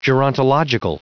Prononciation du mot gerontological en anglais (fichier audio)
Prononciation du mot : gerontological